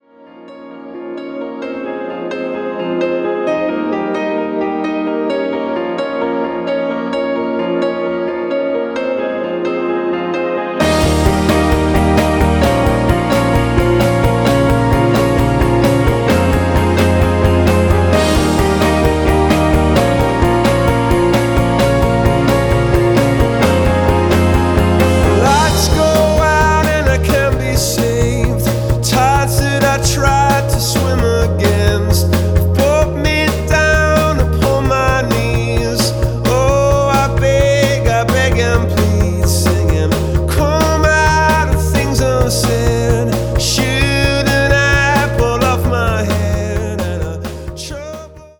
• Качество: 320, Stereo
мужской вокал
Alternative Rock
пианино
психоделический рок